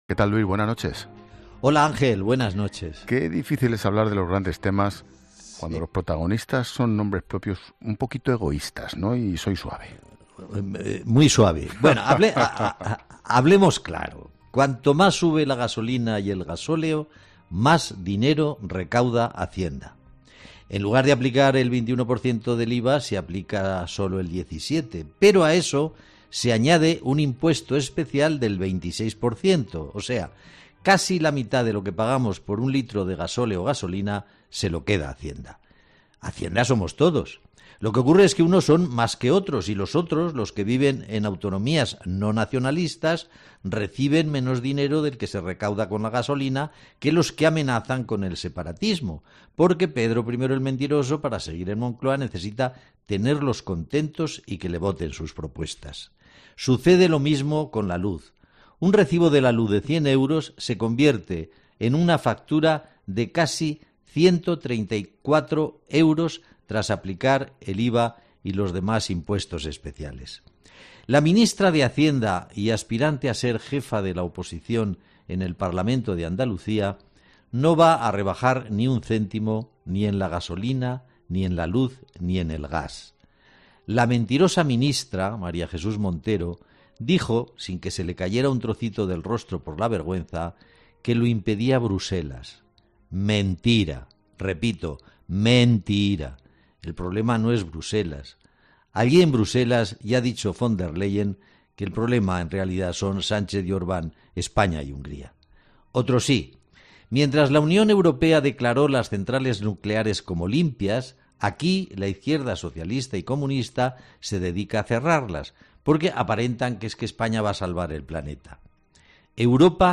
El periodista Luis del Val pone el foco en 'La Linterna' en la alta carga impositiva de los carburantes y la electricidad que el Gobierno se niega a rebajar